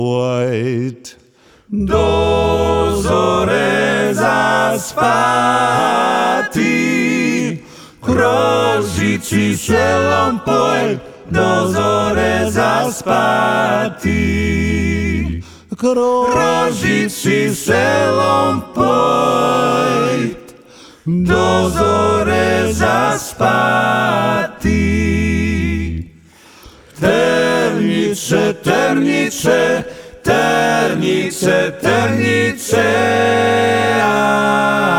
Traditional Pop Vocal